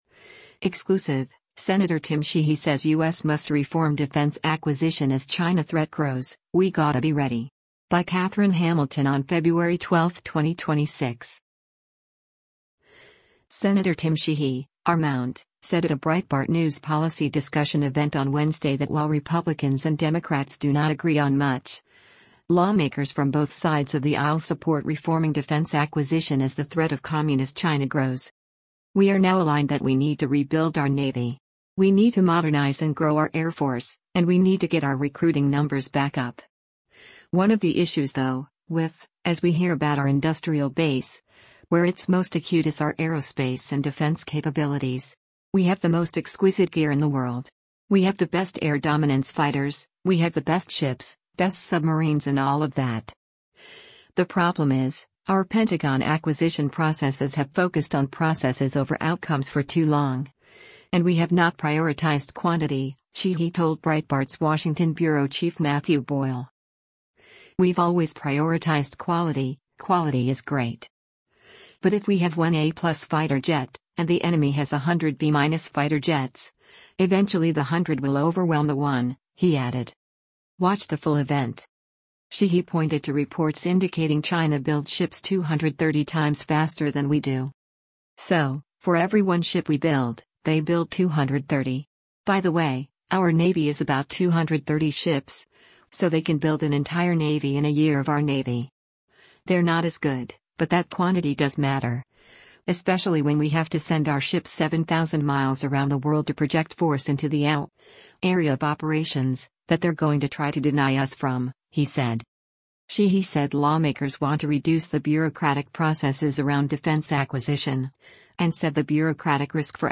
Sen. Tim Sheehy (R-MT) said at a Breitbart News policy discussion event on Wednesday that while Republicans and Democrats do not agree on much, lawmakers from both sides of the aisle support reforming defense acquisition as the threat of communist China grows.